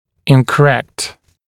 [ˌɪnkə’rekt][ˌинкэ’рэкт]неправильный, неверный, некорректный